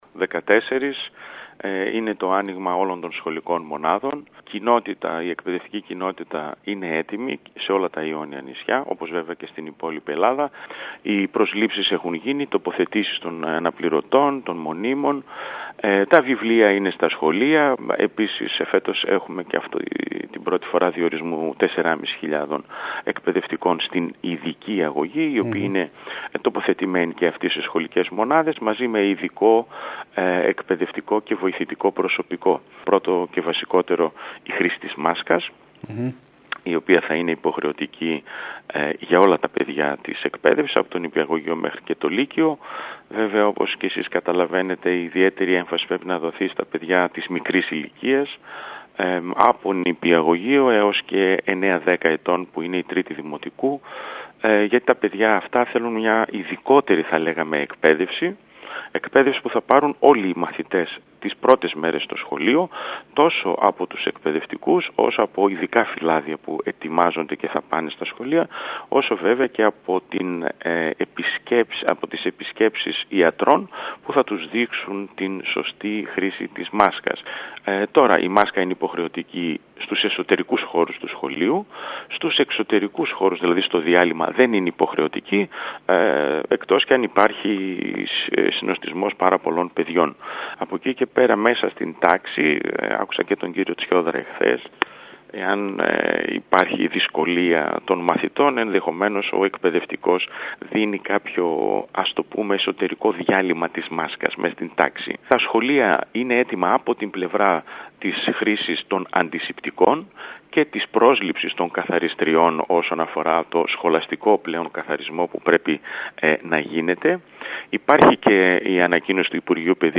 Μιλώντας στην ΕΡΤ Κέρκυρας, ο Περιφερειακός Διευθυντής Εκπαίδευσης Πέτρος Αγγελόπουλος, ανέφερε ότι ήδη έχει τοποθετηθεί στη θέση του το διδακτικό προσωπικό αλλά και οι αναπληρωτές ενώ έχουν γίνει προσλήψεις και για τις καθαρίστριες που προβλέπεται να παρεμβαίνουν και κατά τη διάρκεια της λειτουργίας των σχολείων.